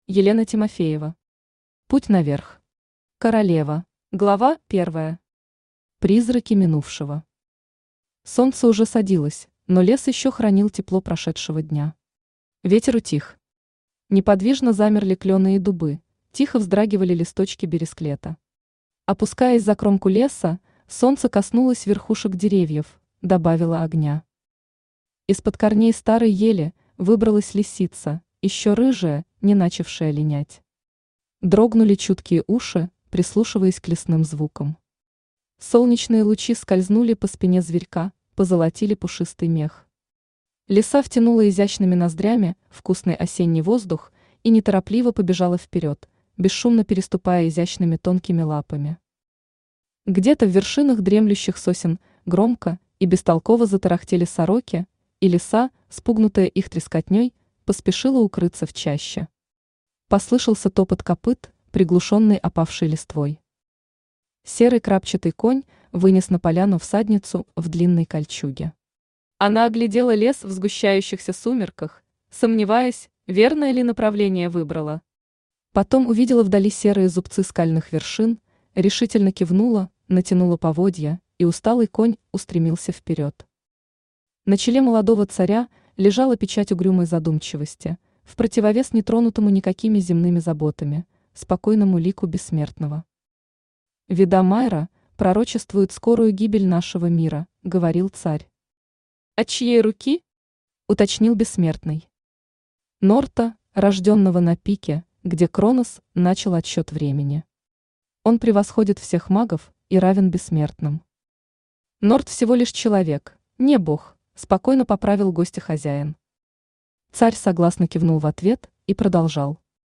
Королева Автор Елена Александровна Тимофеева Читает аудиокнигу Авточтец ЛитРес.